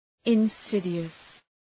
{ın’sıdıəs}